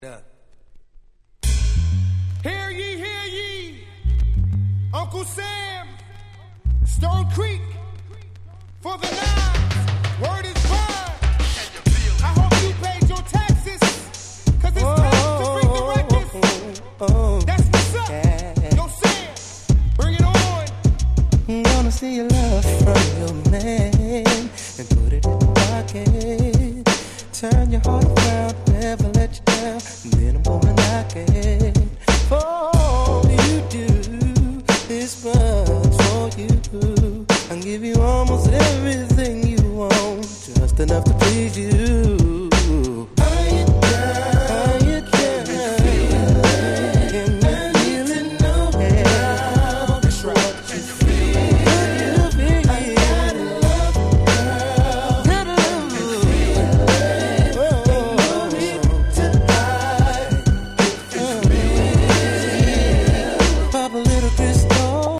97' Nice R&B / Hip Hop Soul !!